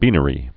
(bēnə-rē)